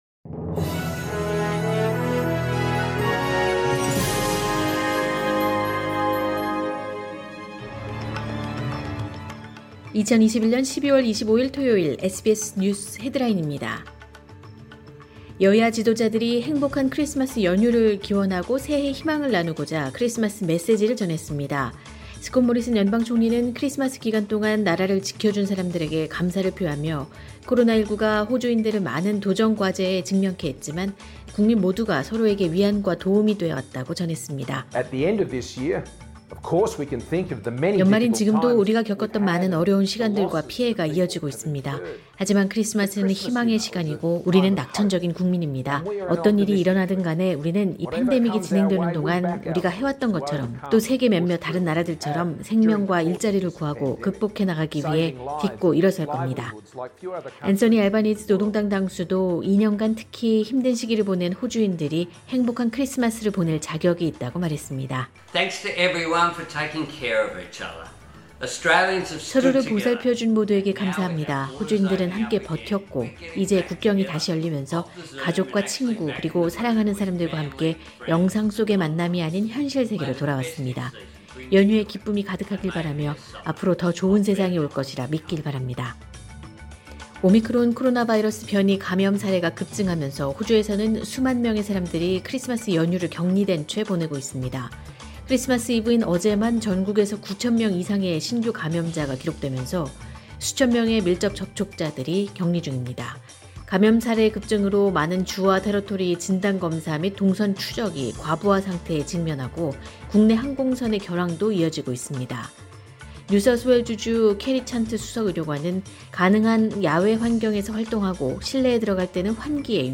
“SBS News Headlines” 2021년 12월 25일 주요 뉴스
2021년 12월 25일 토요일 크리스마스 SBS 뉴스 헤드라인입니다.